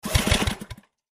in_generator_pull_04_hpx
Electric generator starts and sputters then shuts off. Electric Generator Motor, Generator Engine, Generator